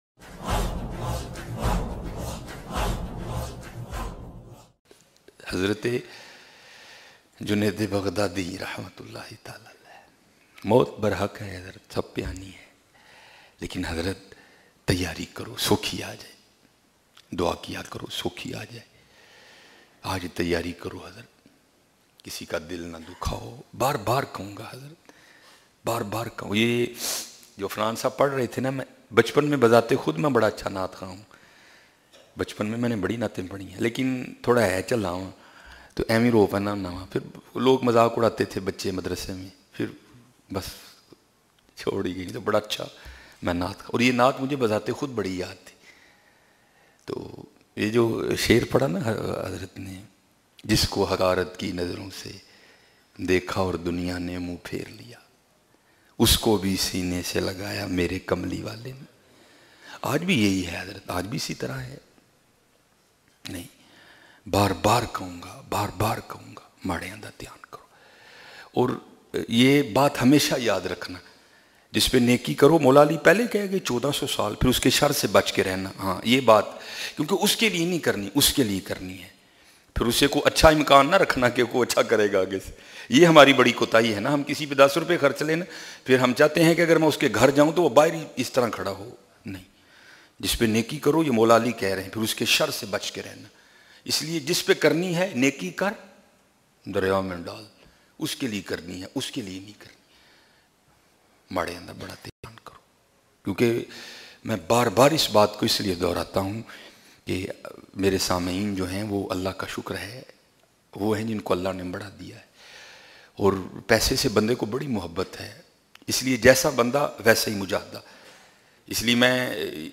Bayan MP3